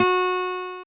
mariopaint_plane.wav